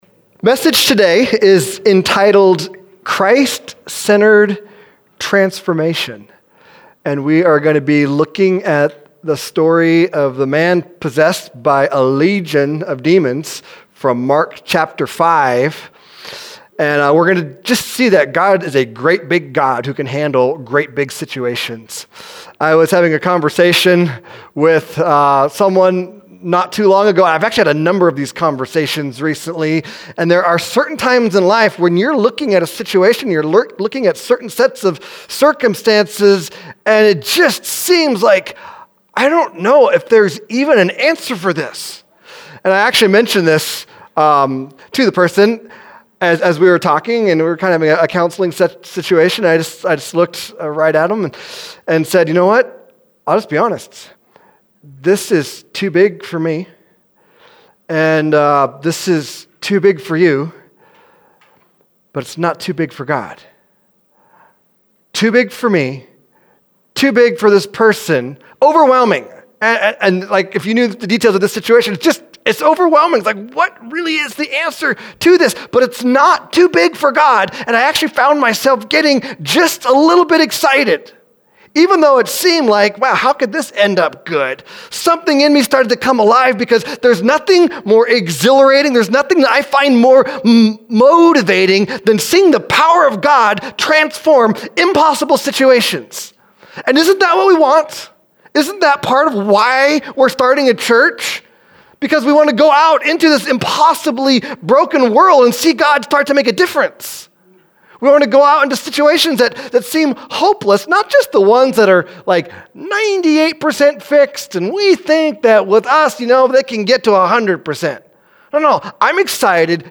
cpc-8-20-17-sermon-no-edit.mp3